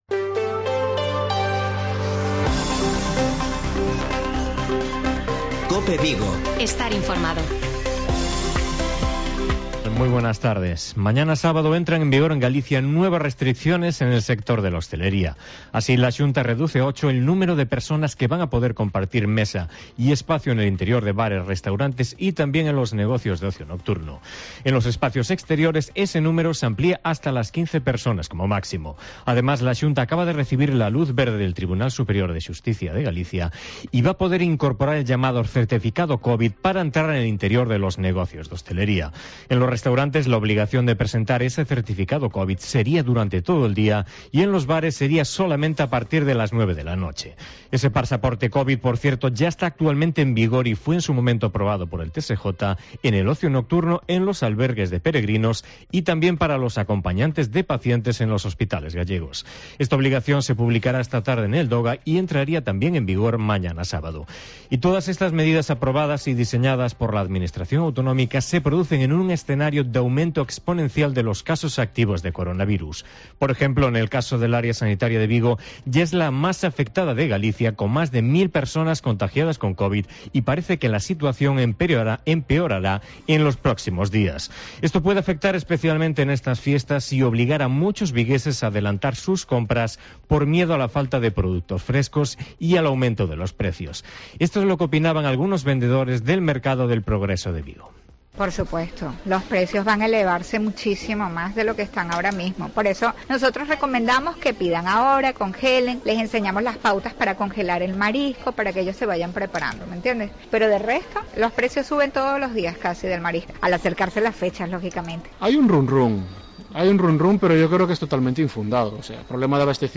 INFORMATIVO COPE VIGO 26-11-21